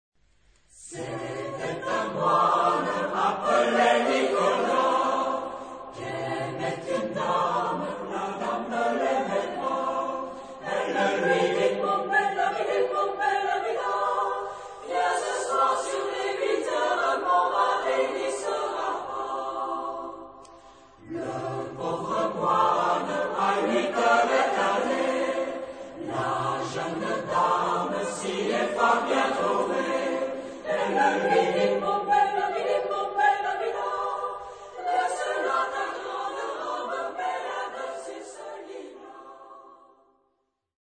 Genre-Style-Form: Secular ; Drinking song
Type of Choir: SATB  (4 mixed voices )
Tonality: A major
Origin: France ; Poitou (F)